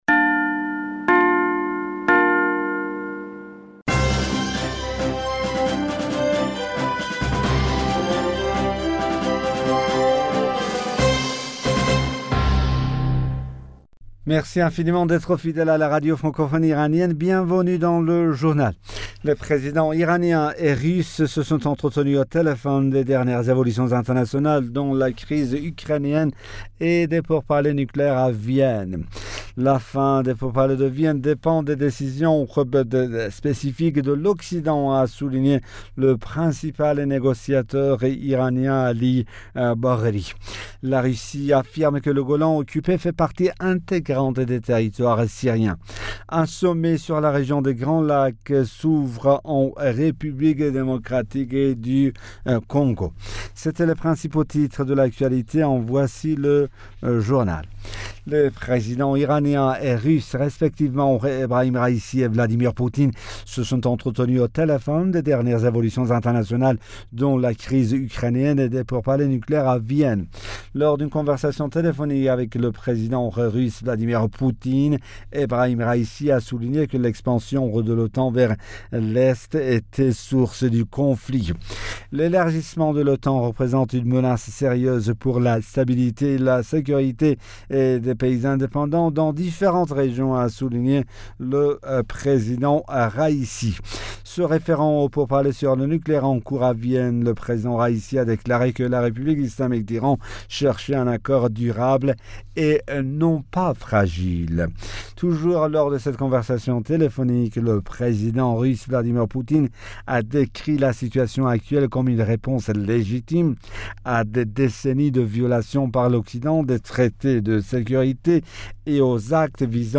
Bulletin d'information Du 25 Fevrier 2022